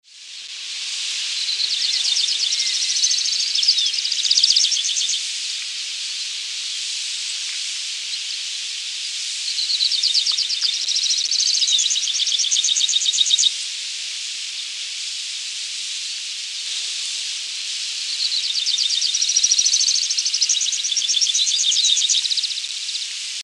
Cachirla Trinadora (Anthus chacoensis)
Varios ejemplares volando alto y emitiendo la vocalización típica.
Nombre en inglés: Pampas Pipit
Localidad o área protegida: Saladillo
Certeza: Vocalización Grabada